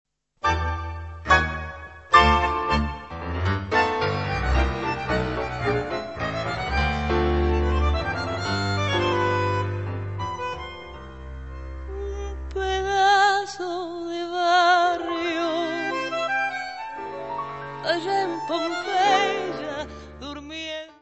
Bandoneón
piano
contrabaixo
Music Category/Genre:  World and Traditional Music